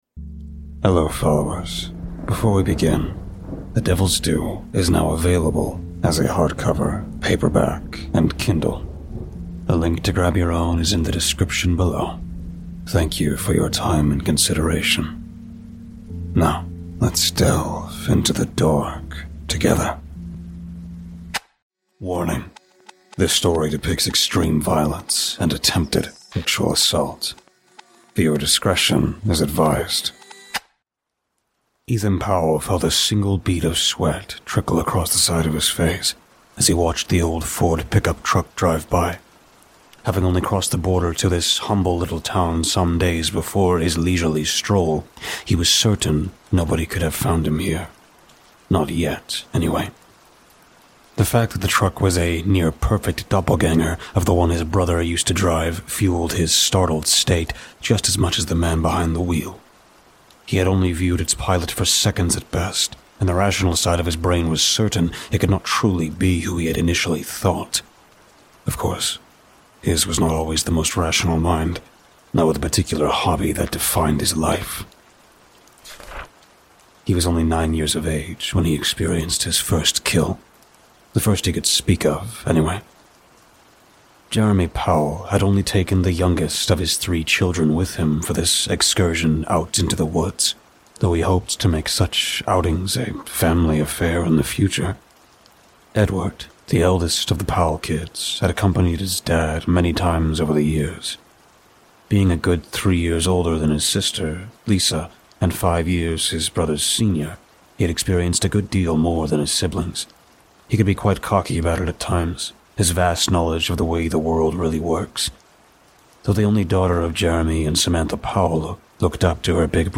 Sad Piano Music